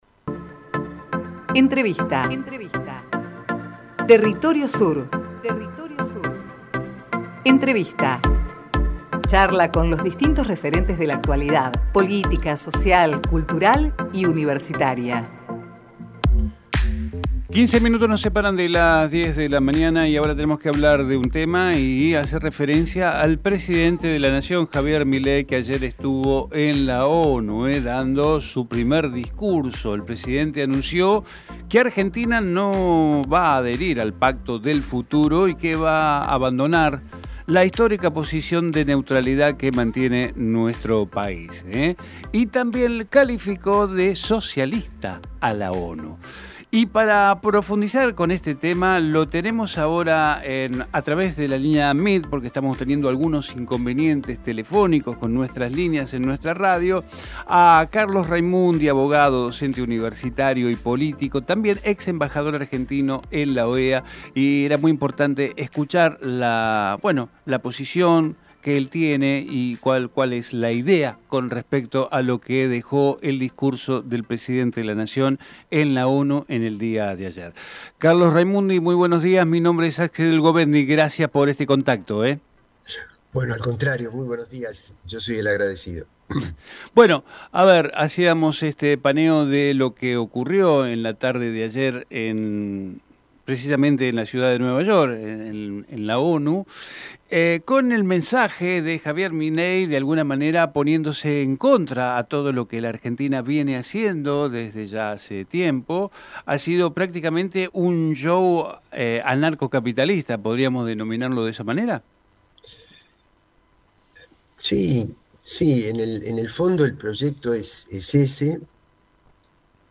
TERRITORIO SUR - Carlos Raimundi Texto de la nota: Entrevista a Carlos Raimundi - Abogado, docente universitario y político y ex embajador argentino en la OEA Archivo de audio: TERRITORIO SUR - Carlos Raimundi Programa: Territorio Sur